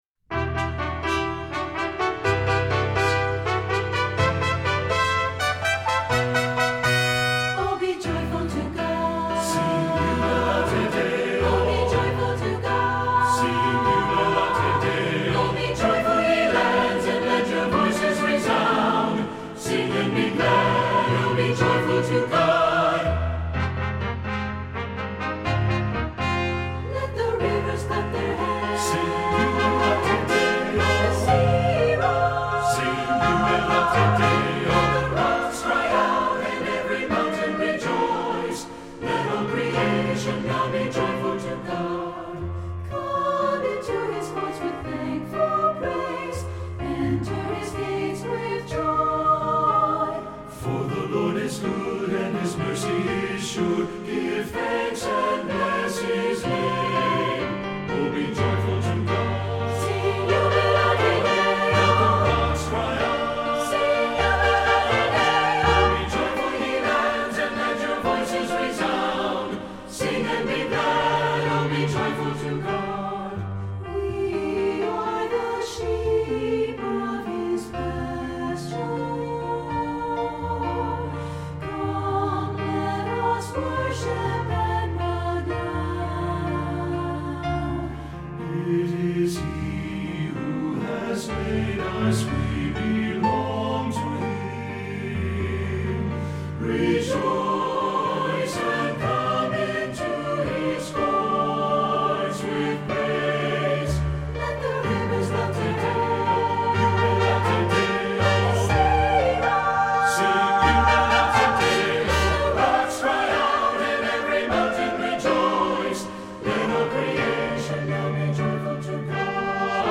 Voicing: SATB and 2 Trumpets